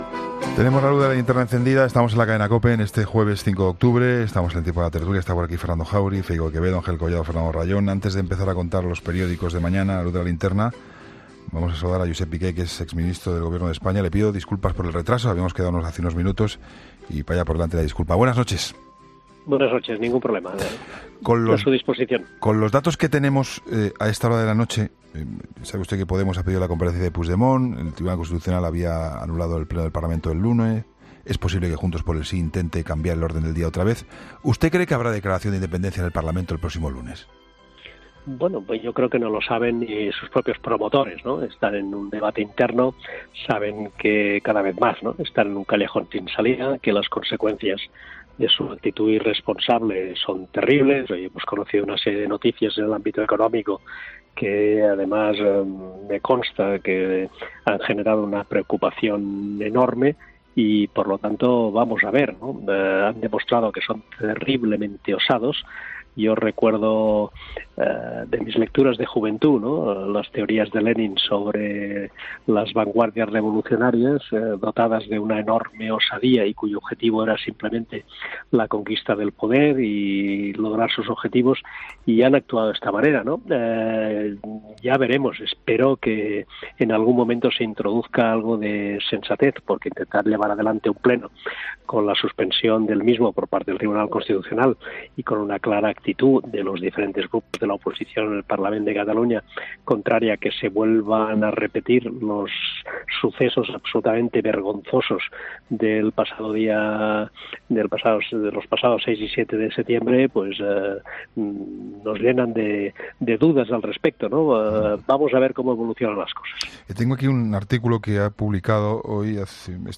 Escucha la entrevista al exministro Jospe Piqué en 'La Linterna'